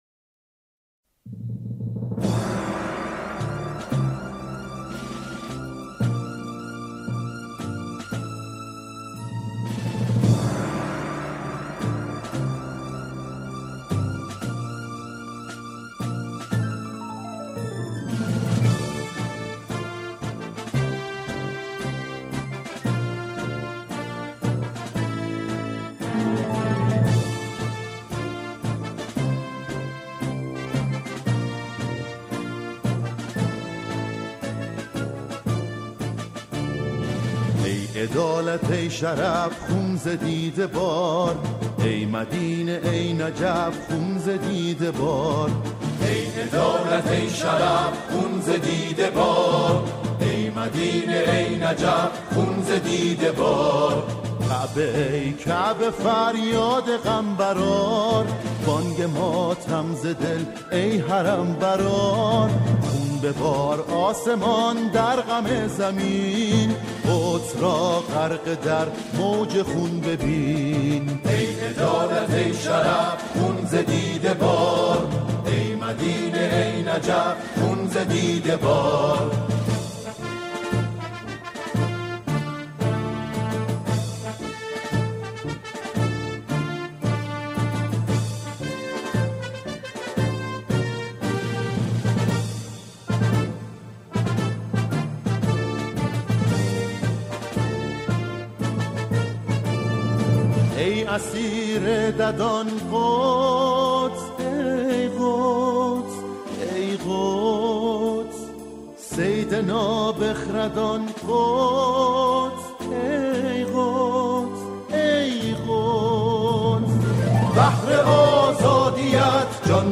سرودهای فلسطین